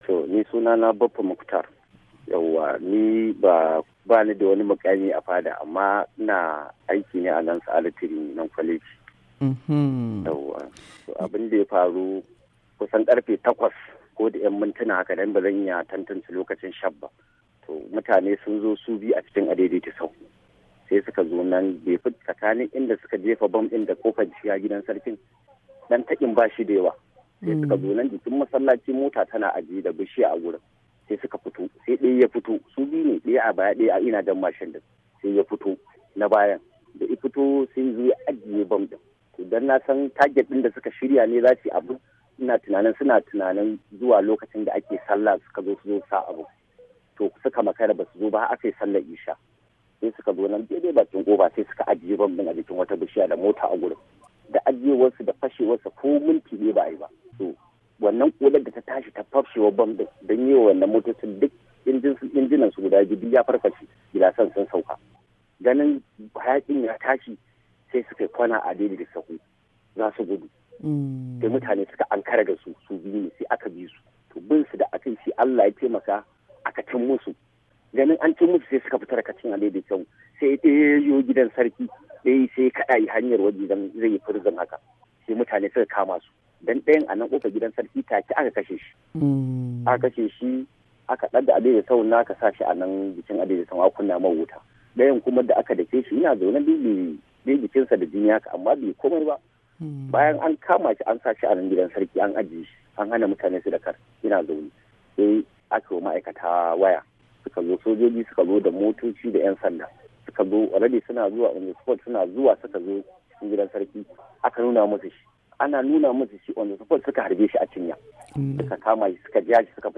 Tattaunawar